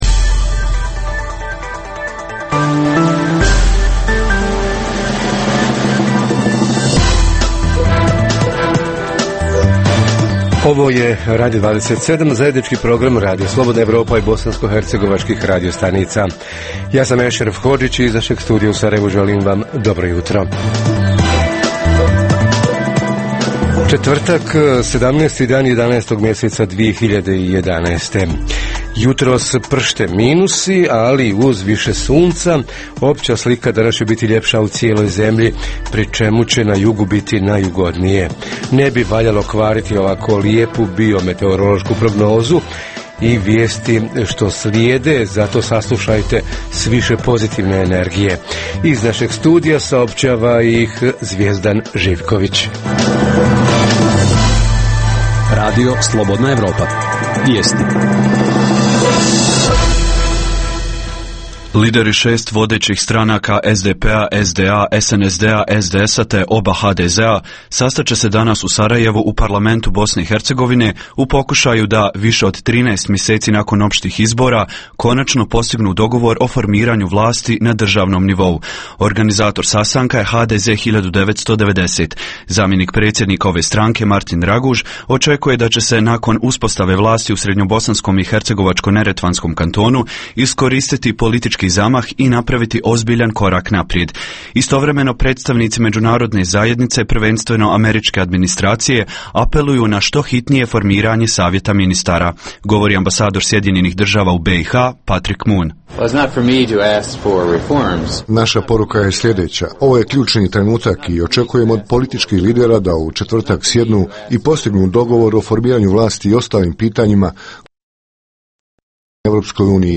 Komunalna preduzeća na početku zimske sezone – jesu li sve pripreme obavljene na vrijeme, prije svega zimska putna služba, zatim toplane i centralno grijanje, javni gradski i prigradski prevoz itd. Reporteri iz cijele BiH javljaju o najaktuelnijim događajima u njihovim sredinama.
Redovni sadržaji jutarnjeg programa za BiH su i vijesti i muzika.